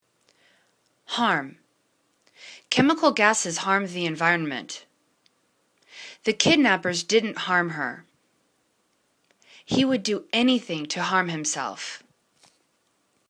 harm    /horm/    v